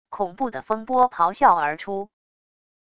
恐怖的风波咆哮而出wav音频